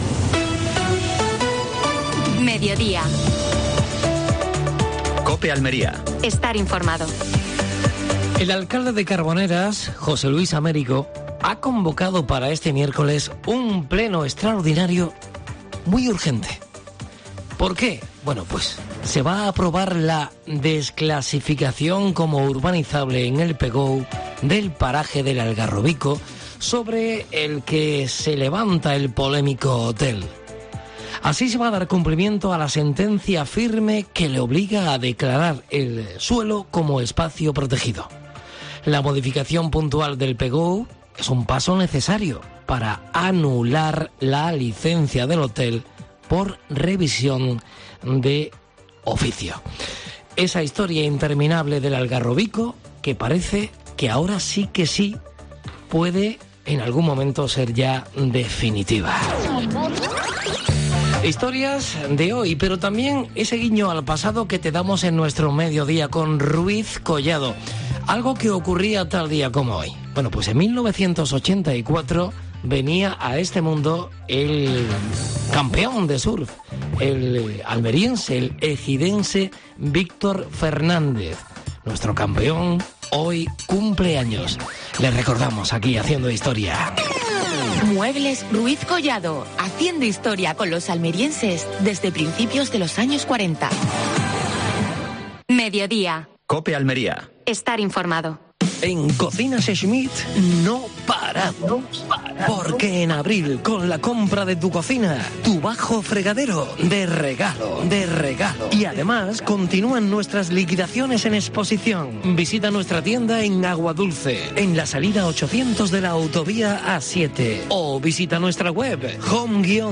Última hora deportiva.